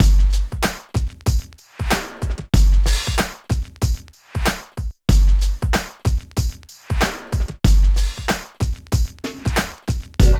69 DRUM LP-R.wav